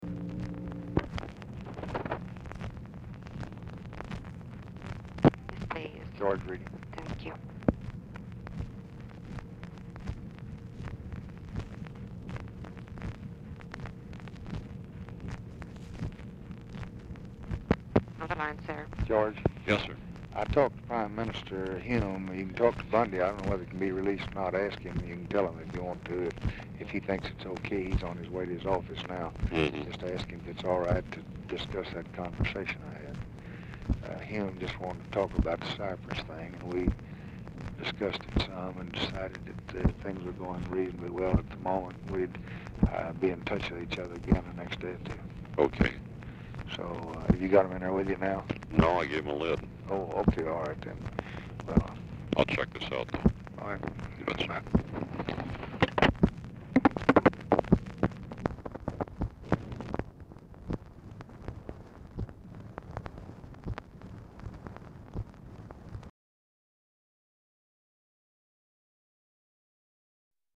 Telephone conversation # 4867, sound recording, LBJ and GEORGE REEDY, 8/10/1964, 5:05PM | Discover LBJ
Format Dictation belt
Location Of Speaker 1 Mansion, White House, Washington, DC